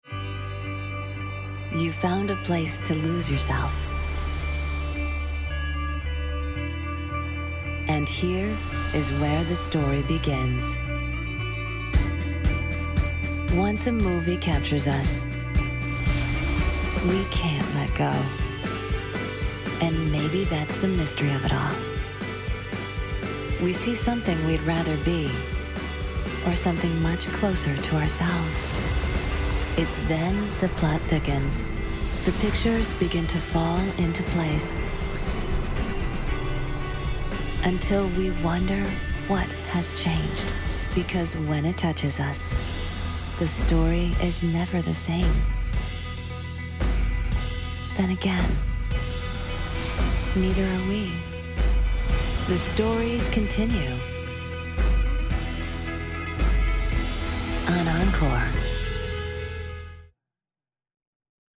Romance Fantasy